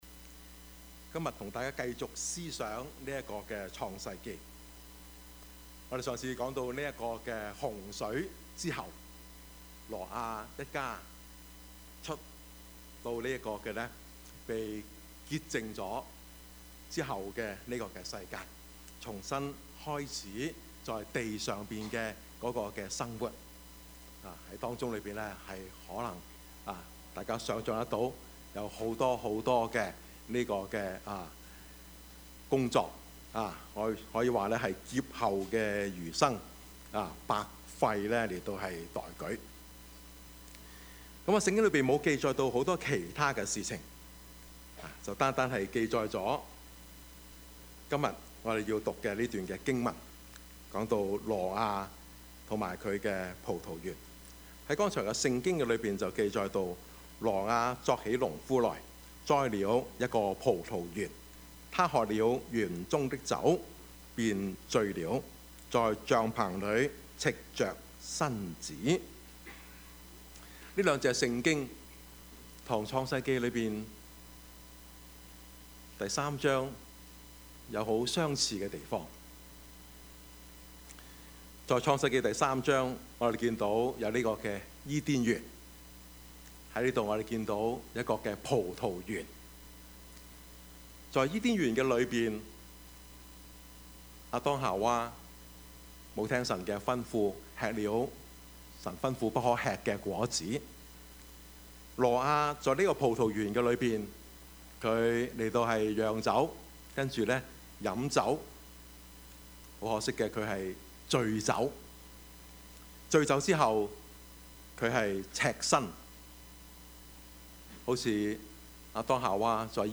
Service Type: 主日崇拜
Topics: 主日證道 « 與神同行 袁世凱與辛亥革命 »